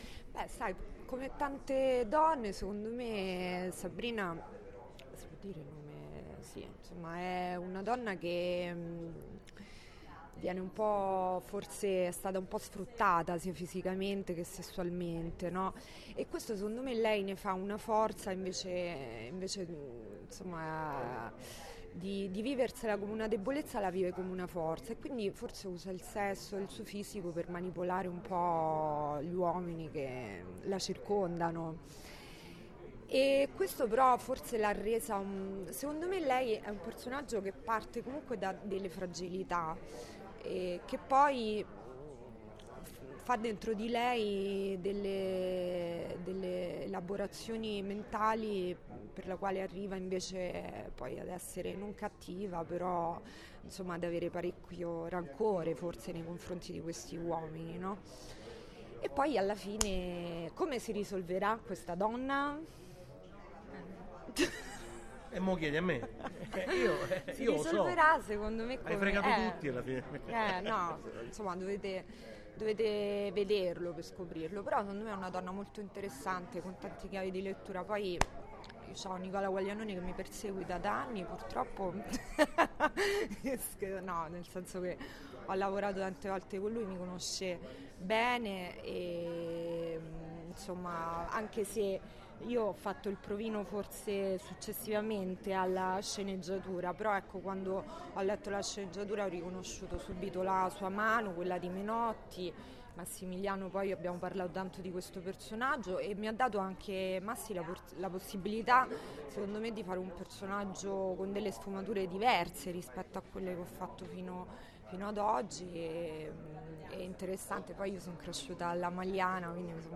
non-ci-resta-che-il-crimine-ilenia-pastorelli-parla-del-ruolo.mp3